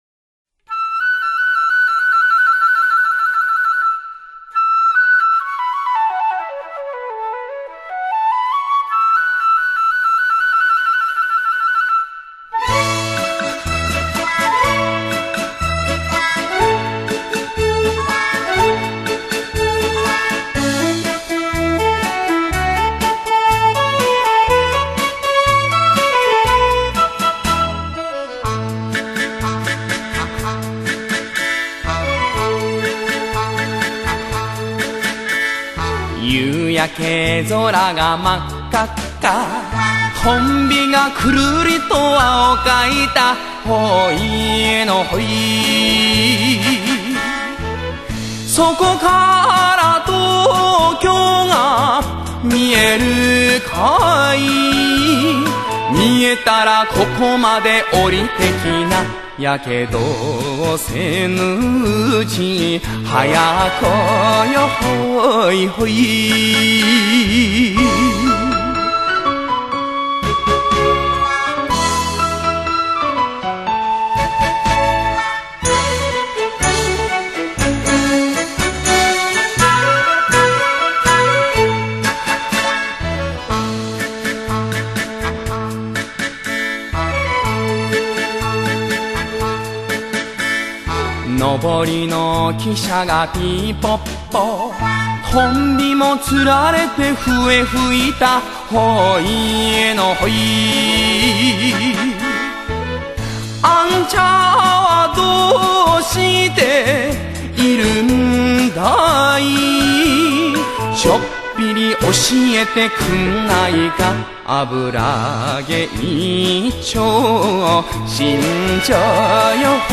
のぞかせてくれる演歌界のプリンス。